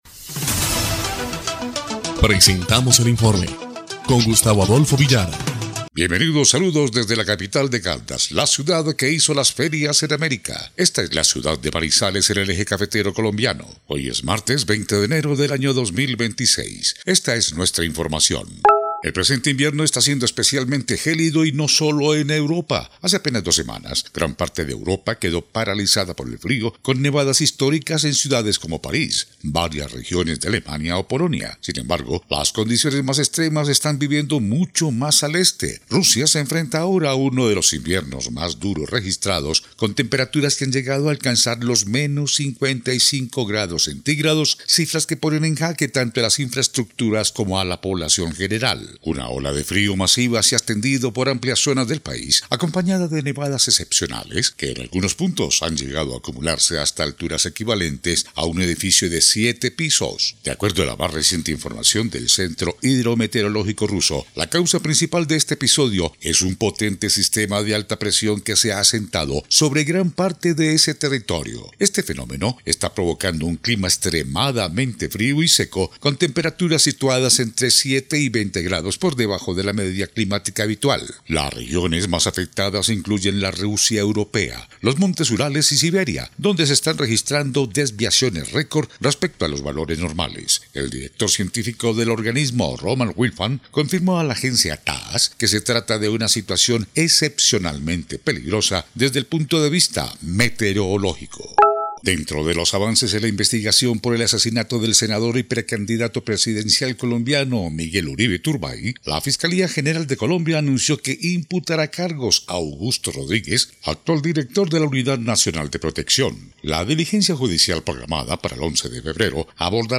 EL INFORME 1° Clip de Noticias del 20 de enero de 2026